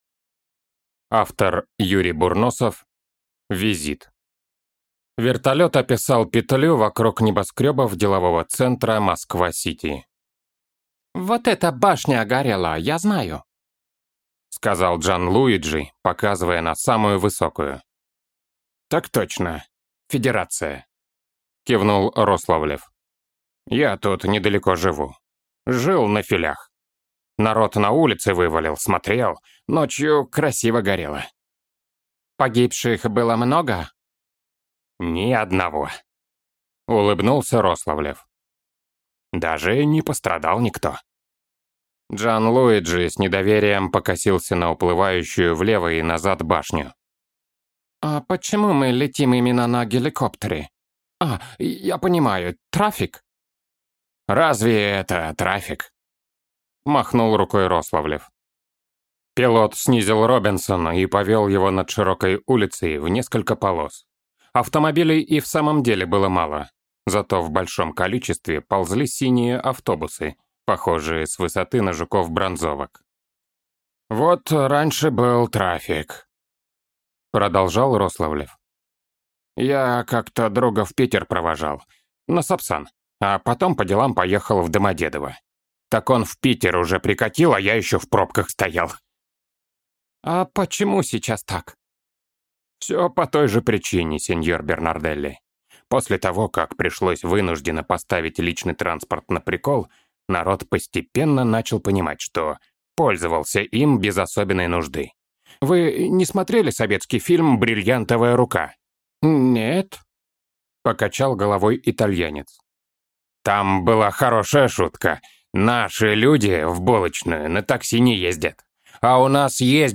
Аудиокнига Визит | Библиотека аудиокниг
Прослушать и бесплатно скачать фрагмент аудиокниги